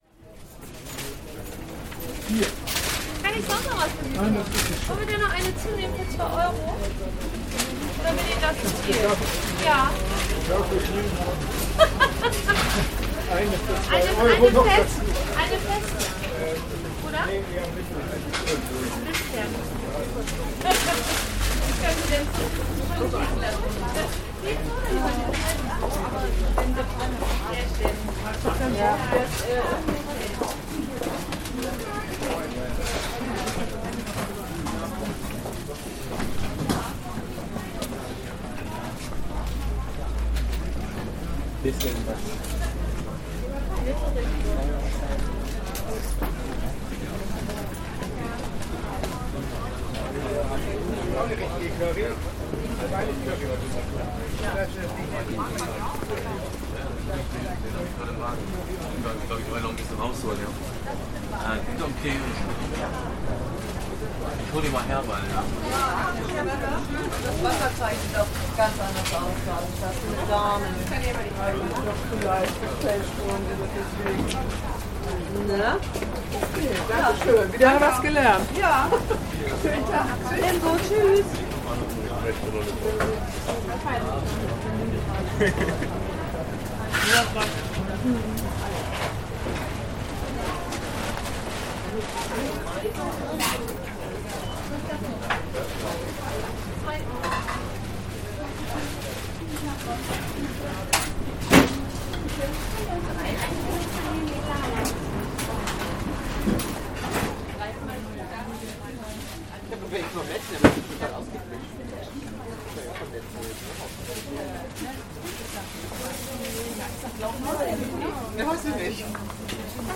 Food market at Grossneumarkt, Hamburg
The weekly Wednesday lunchtime market at Grossneumarkt, October 2014.